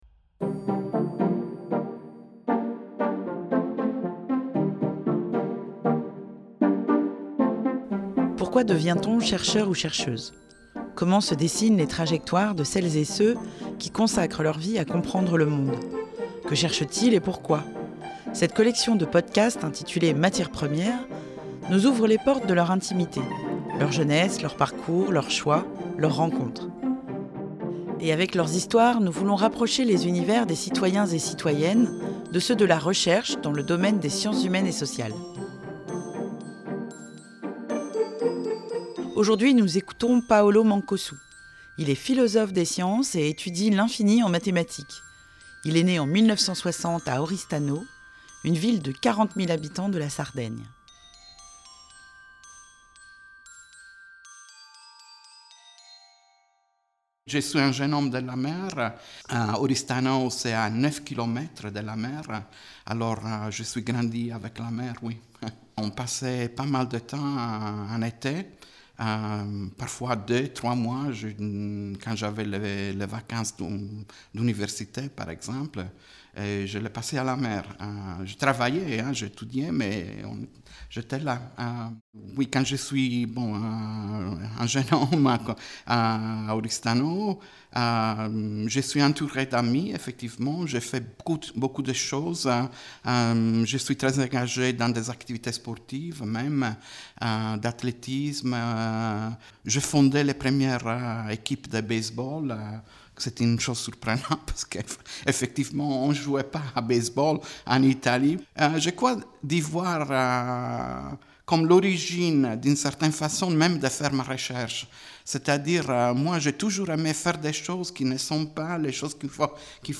Favoris Ajouter à une playlist Entretien